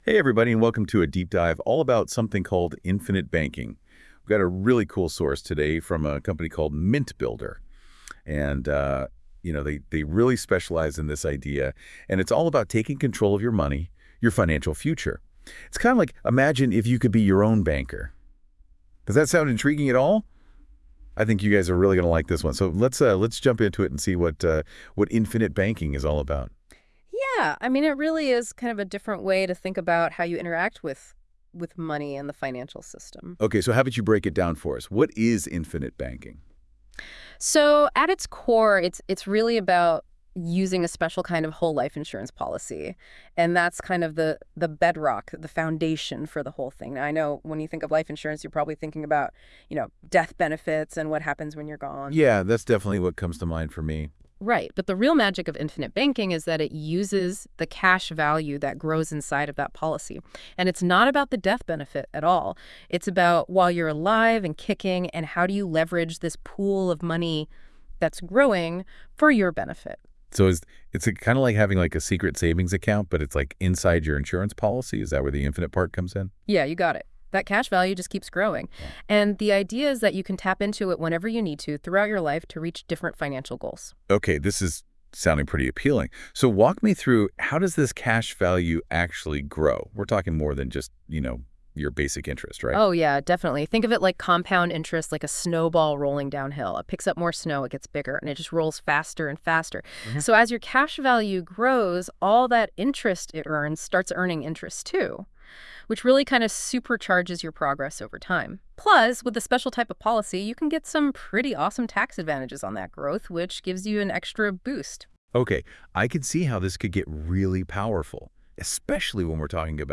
Infinitive-banking-conversation.wav